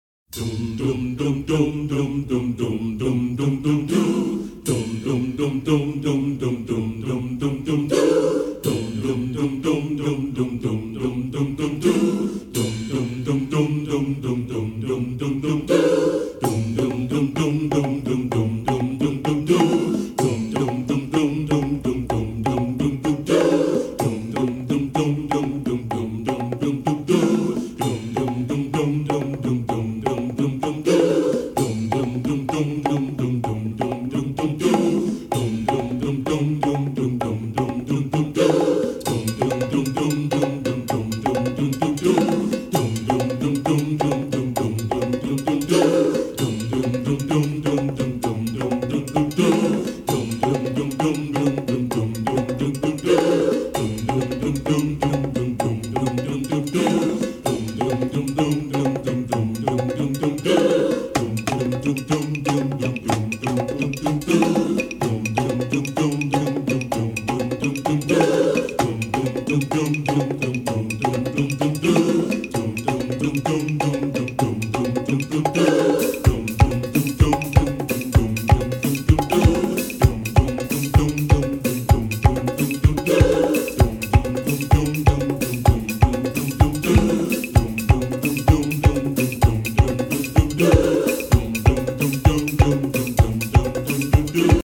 Music for Ailey School Horton